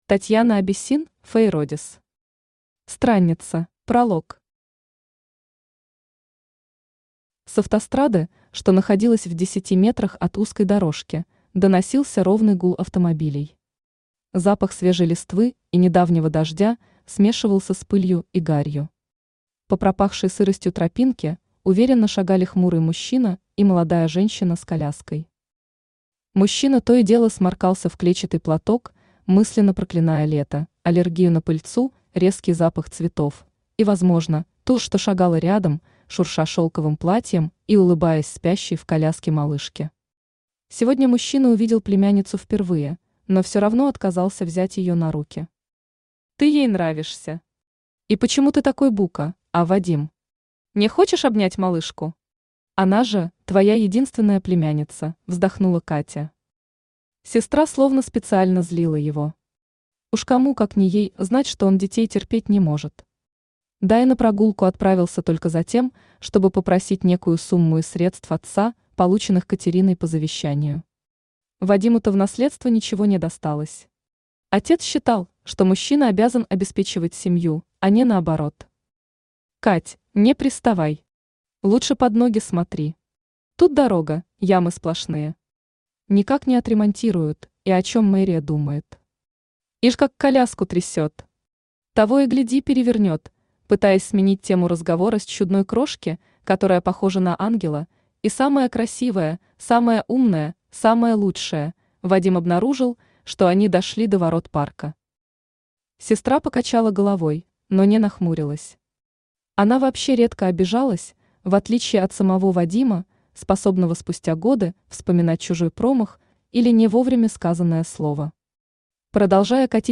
Aудиокнига Странница Автор Татьяна Абиссин Читает аудиокнигу Авточтец ЛитРес.